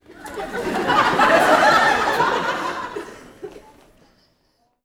Audience Laughing-04.wav